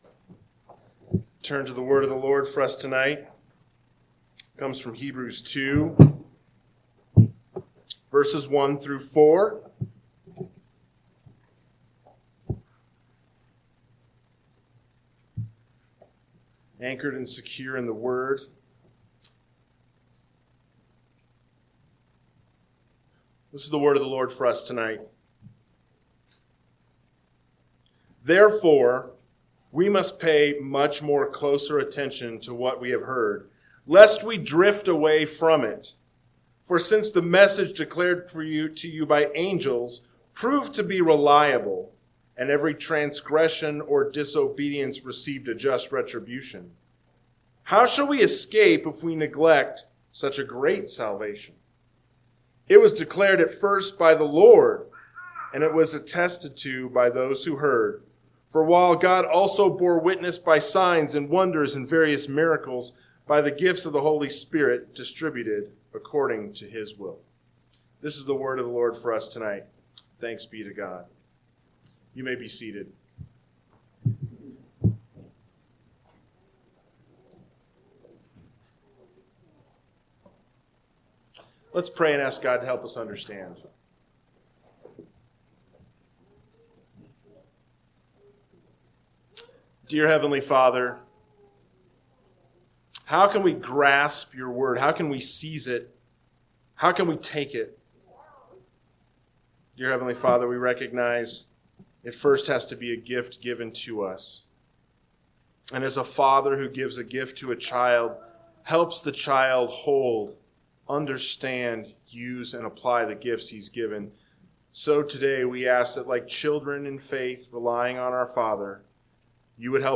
PM Sermon – 11/23/2025 – Hebrews 2:1-4 – Northwoods Sermons